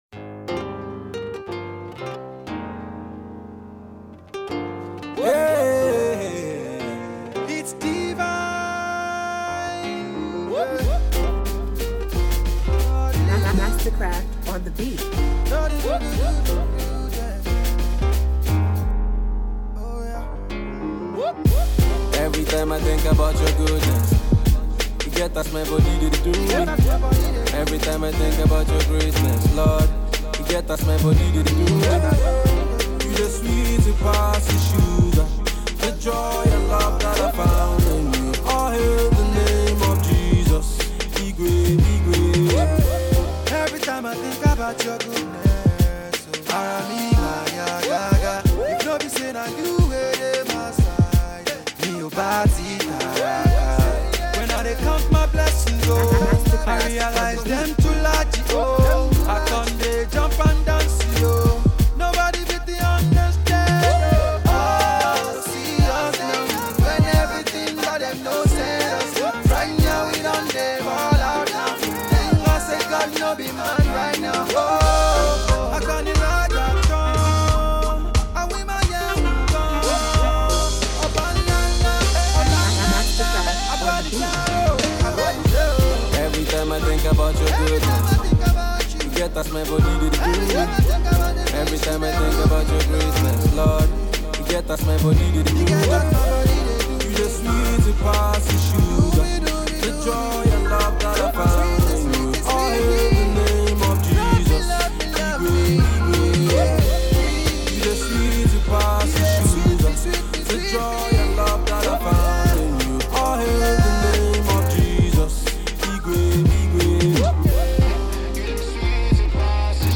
praise R n B rendition